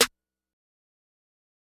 Metro Snare 12.wav